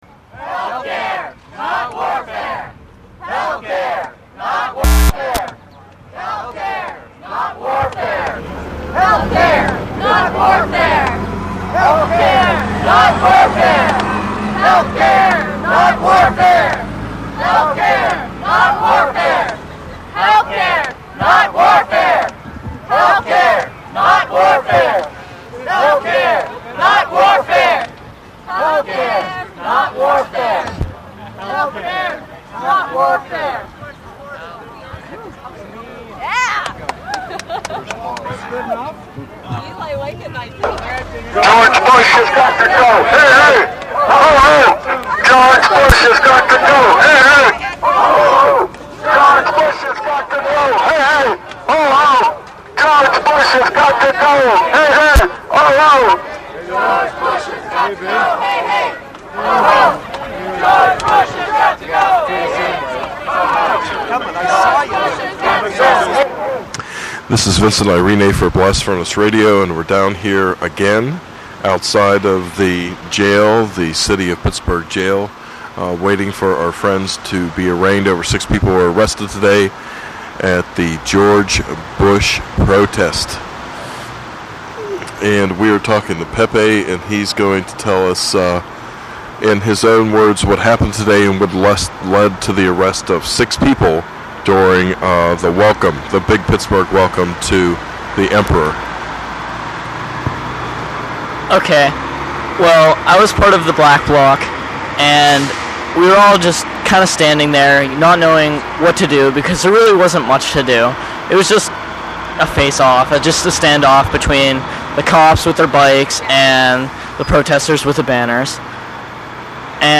audio of protest and interview at the jail solidarity vigil after 6 people were arrested at the protest at bush's fundraiser. 7 min. 56 secs 56k